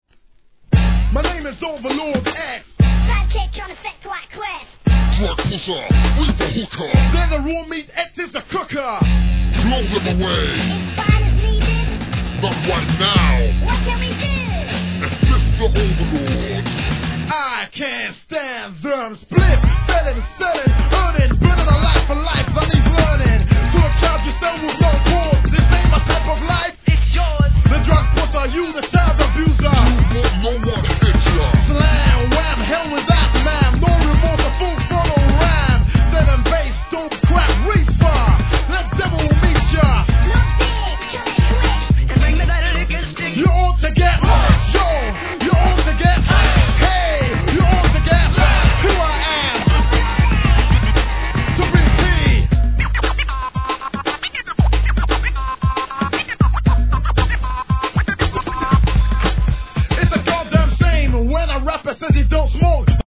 1990年、UK HIP HOP!! テンション高めのRAPとその天性の声質にやられます!